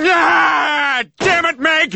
Worms speechbanks
runaway.wav